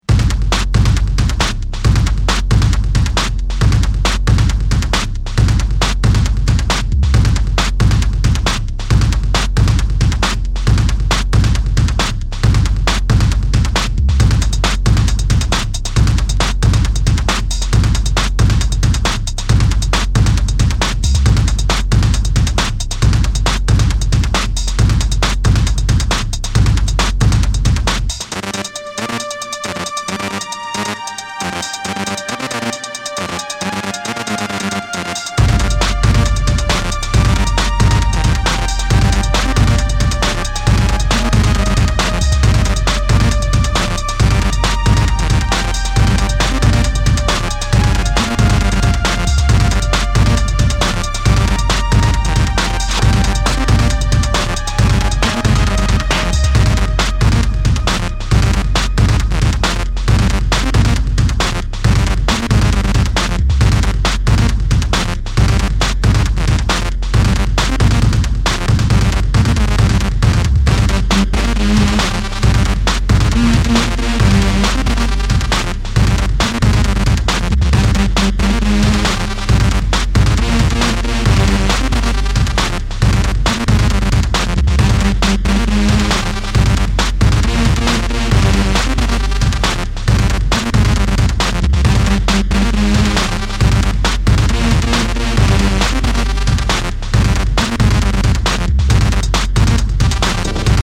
尖ったインダストリアル・ビート主体ながらエレクトロ、テクノ、ミニマルをもACID感覚も絡めつつ突き進む狂気の全13曲！